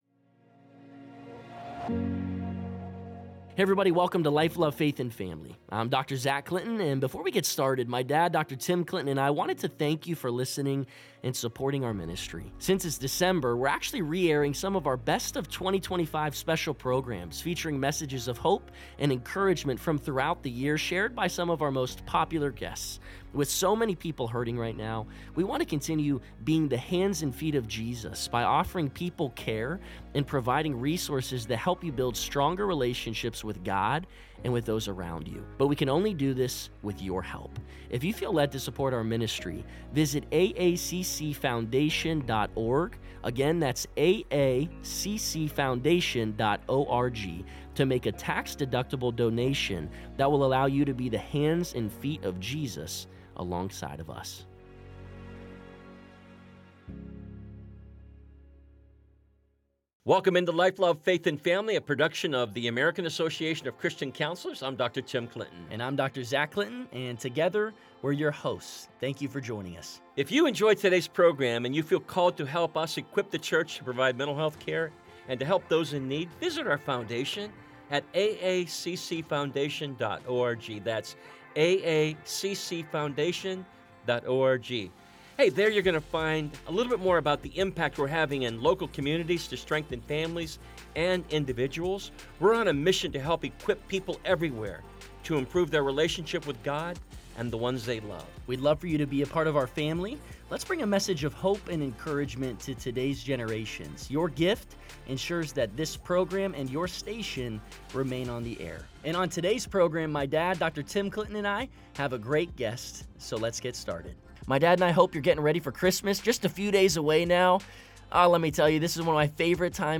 In a conversation